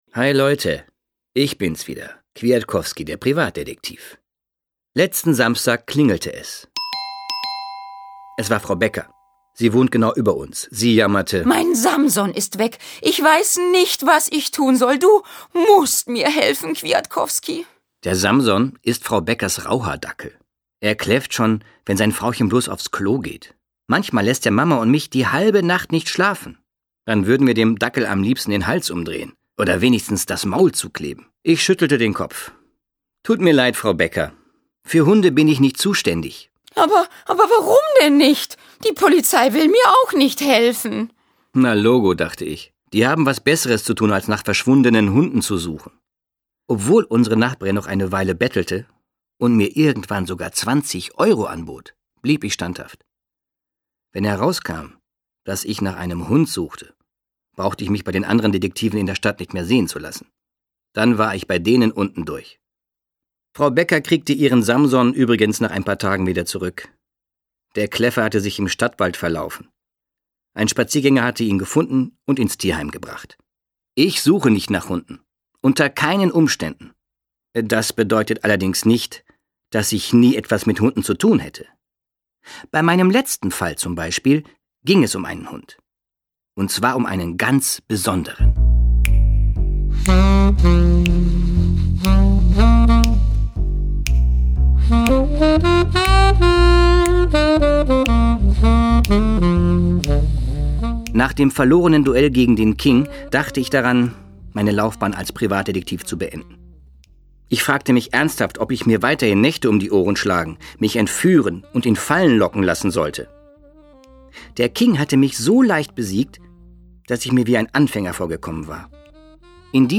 Schlagworte Detektiv • Detektivgeschichte • Hörbuch für Kinder/Jugendliche • Hörbuch für Kinder/Jugendliche (Audio-CD) • Hörbuch; Lesung für Kinder/Jugendliche • Krimis/Thriller; Kinder-/Jugendliteratur • Krimis/Thriller; Kinder-/Jugendliteratur (Audio-CDs) • Privatdetektiv